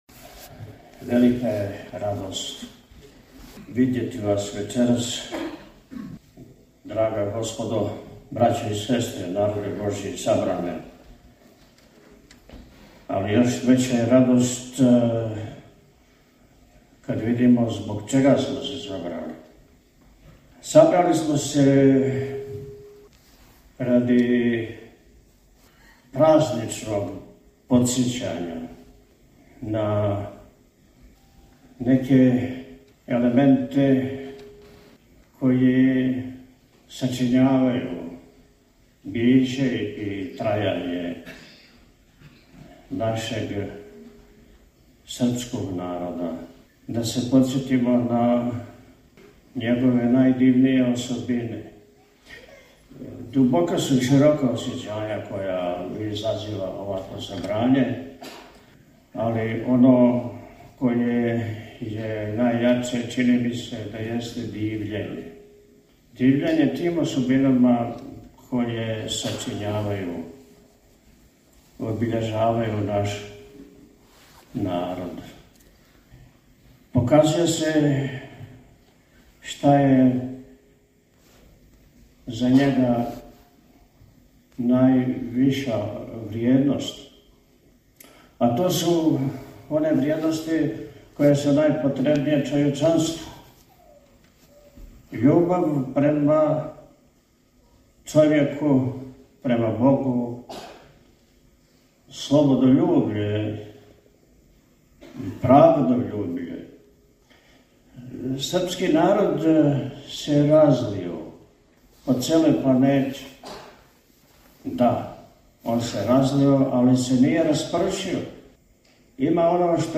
Поводом обележавања значајних јубилеја, 220 година од Првог српског устанка и 189 година од доношења Сретењског устава, Општина Пљевља је у навечерје Празнике Сретења Господњег, 14. фебруара 2024. године, организовала Свечану академију у Великој сали Центра за културу Пљевља.